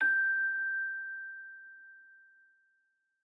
celesta1_7.ogg